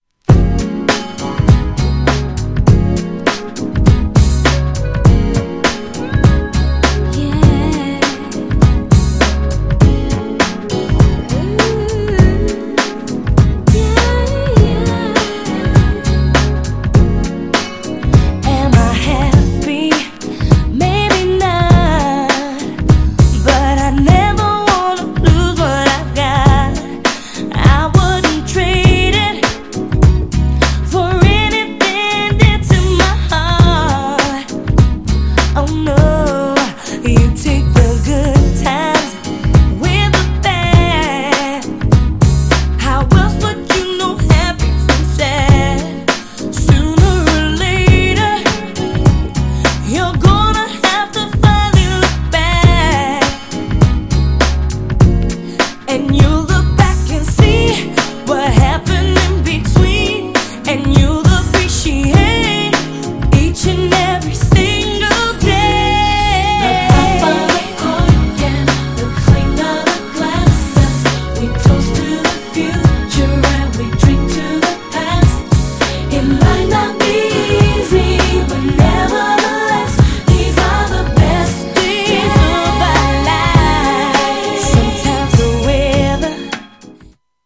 GENRE R&B
BPM 101〜105BPM